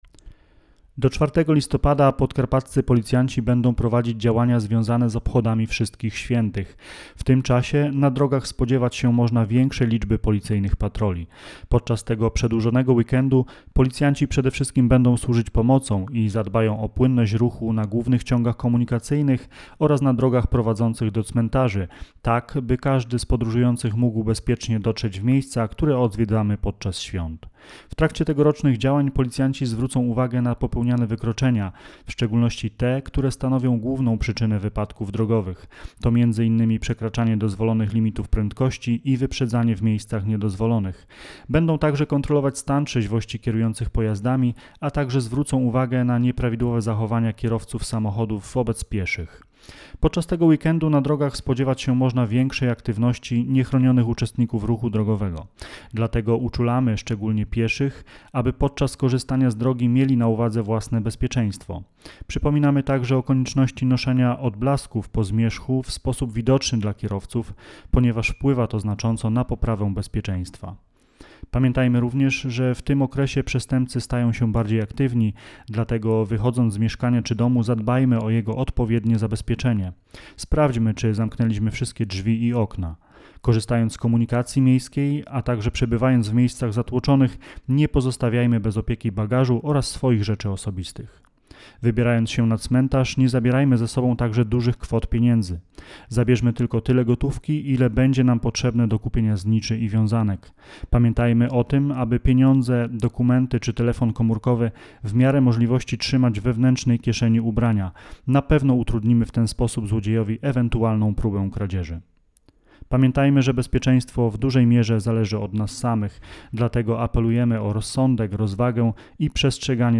Dziś podczas briefingu, policjanci z Komendy Wojewódzkiej Policji w Rzeszowie spotkali się z dziennikarzami, aby przypomnieć o zasadach bezpieczeństwa, zarówno podczas podróży, jak również na cmentarzach i w ich pobliżu.